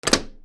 豪邸の玄関扉を開ける 強 02